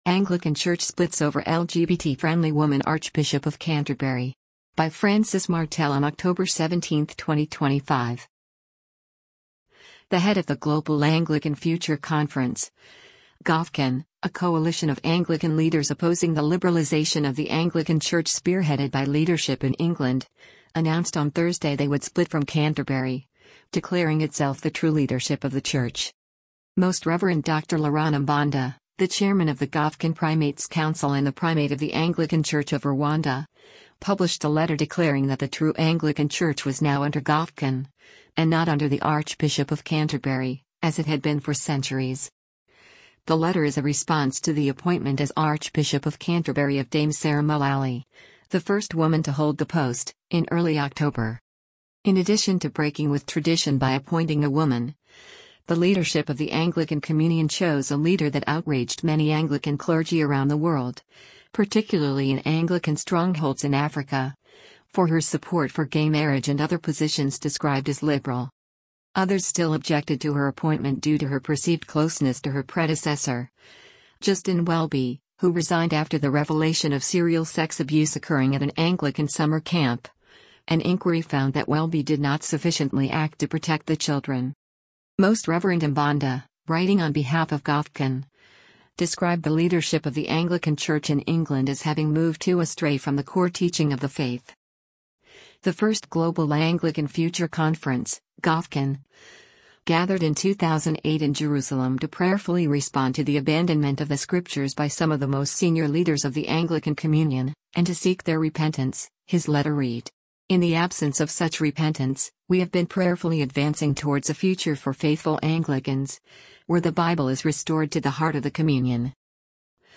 Britain's new Archbishop of Canterbury-designate, Sarah Mullally, speaks during an intervi